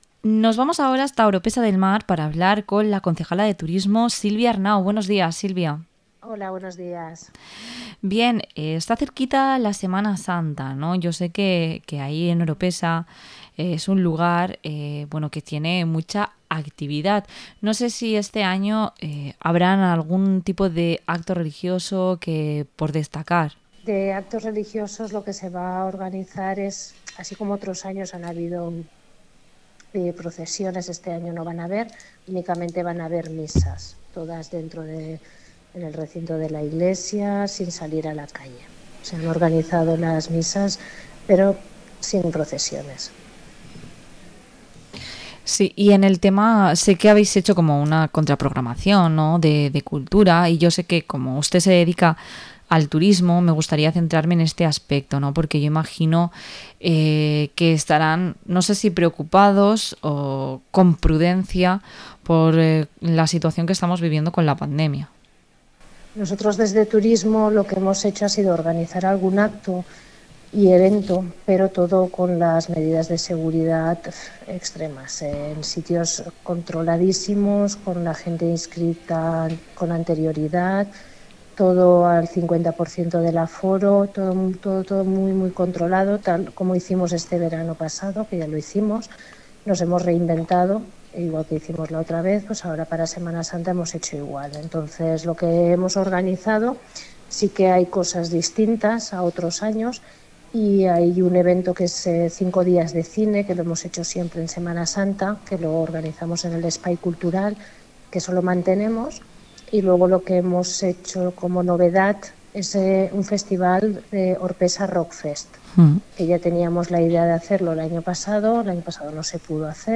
Entrevista a la concejala de Turismo de Oropesa del Mar, Silvia Arnau